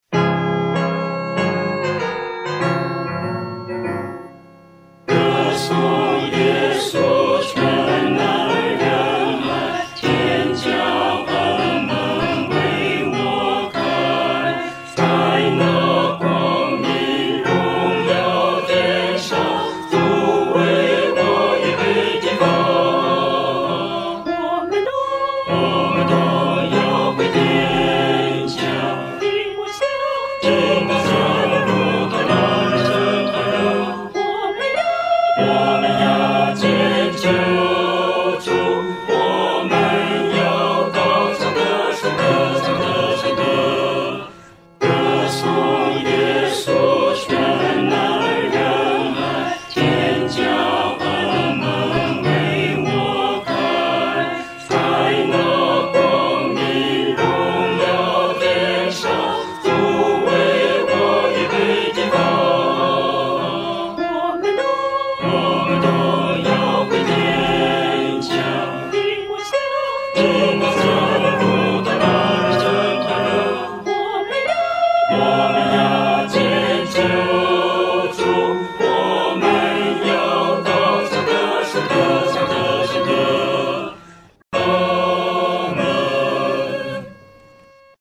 四声